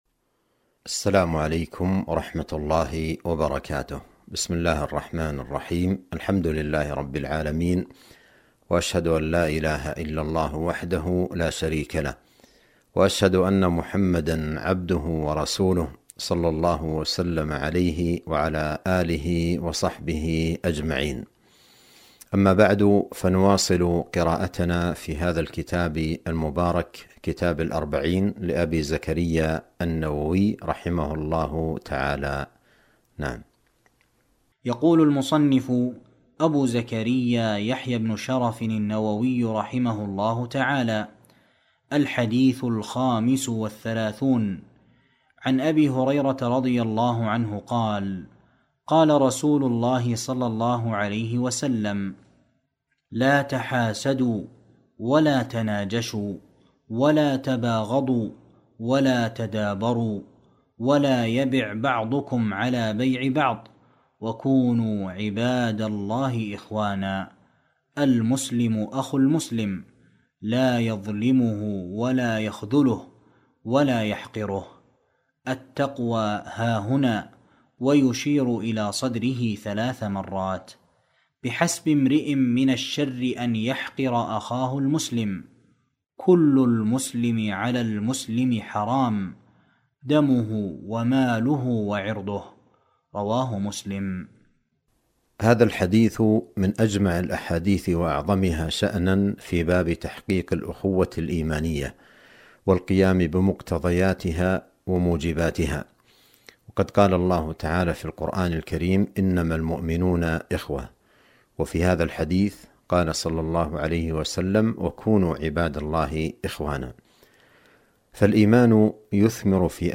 درس في قناة السنة النبوية بالمدينة النبوية